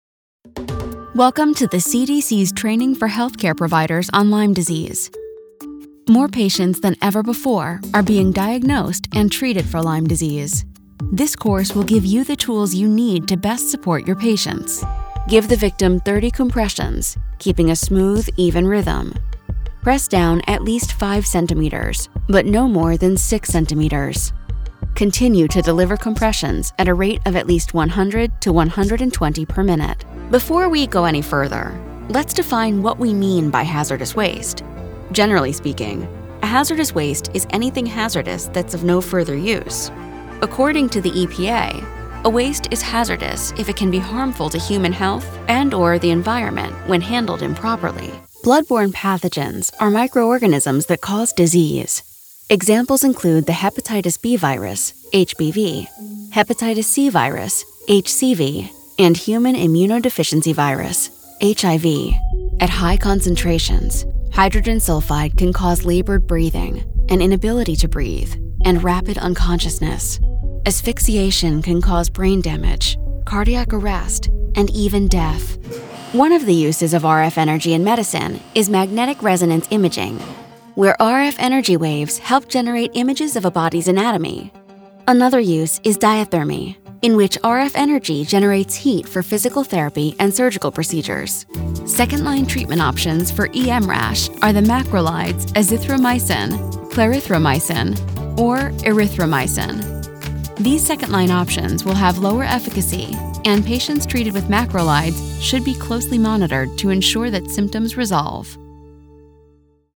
medical narration